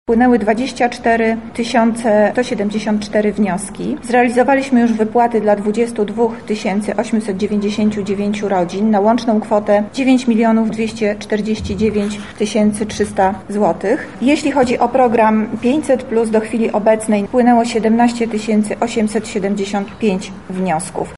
O tym ile wniosków zostało złożonych i ile wpłat już zrealizowano mówi Monika Lipińska, zastępca prezydenta miasta do spaw społecznych: